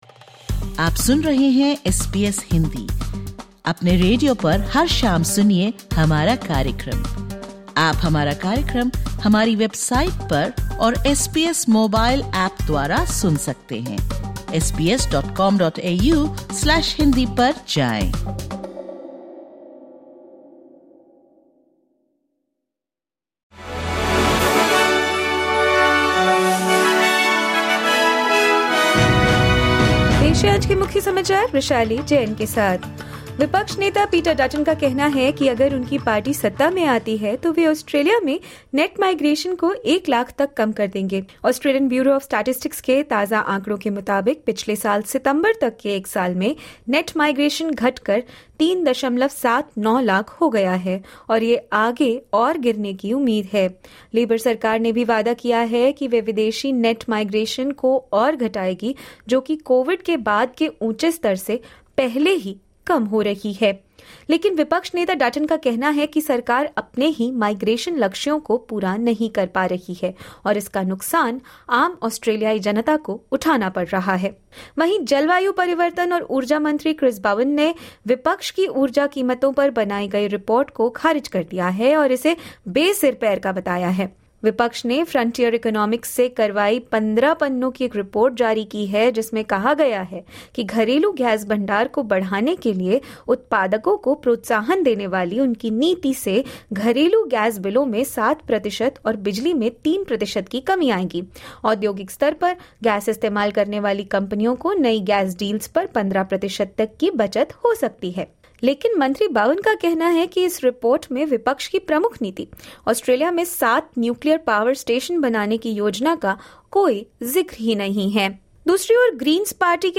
Listen to the top News of 09/04/2025 from Australia in Hindi.